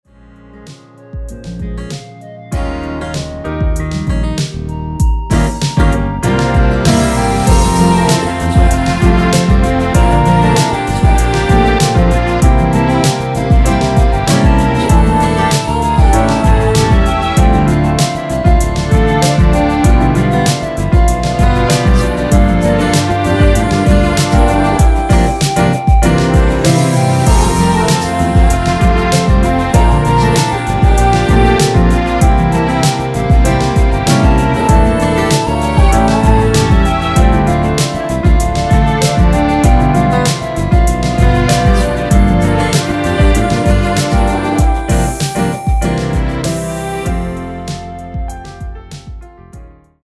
원키에서(-2)내린 멜로디와 코러스 포함된 MR입니다.(미리듣기 확인)
Ab
◈ 곡명 옆 (-1)은 반음 내림, (+1)은 반음 올림 입니다.
앞부분30초, 뒷부분30초씩 편집해서 올려 드리고 있습니다.